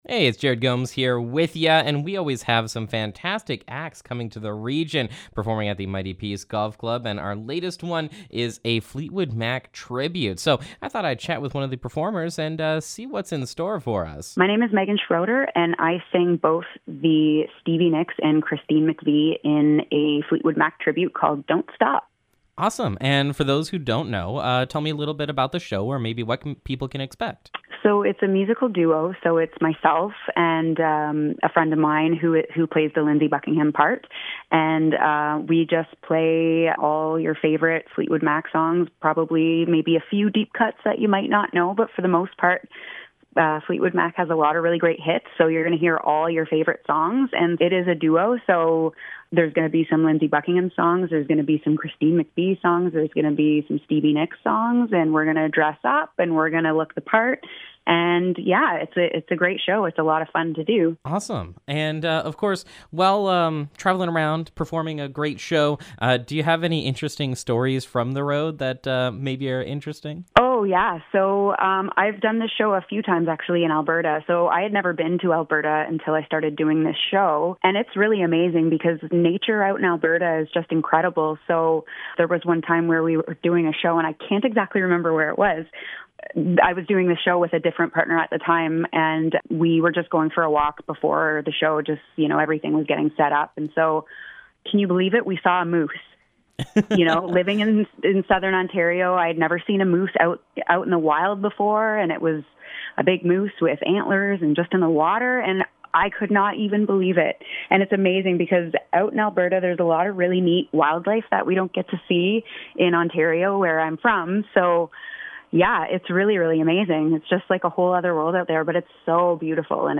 Fleet Wood Mac Tribute Interview
fleet-wood-interview-final.mp3